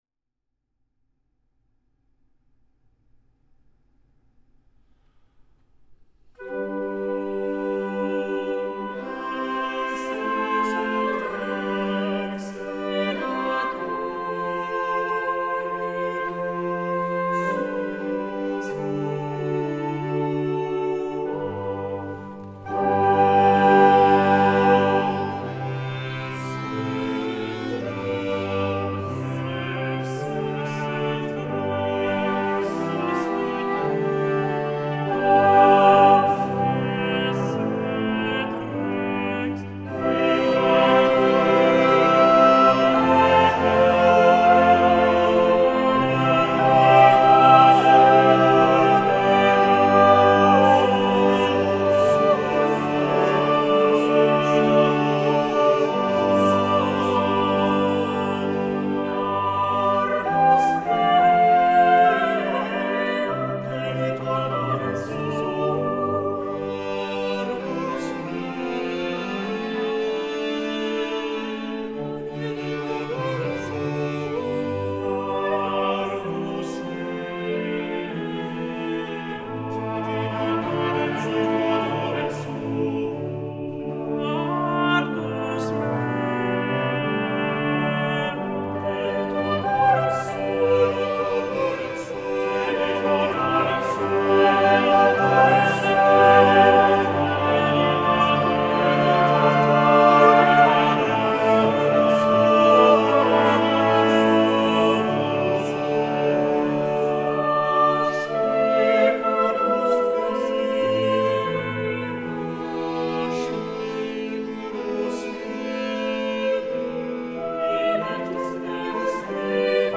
Gli Angeli Vocal and instrumental chamber music of the 17th and 18th centuries 2020
17th Century Sacred Music in Wroclaw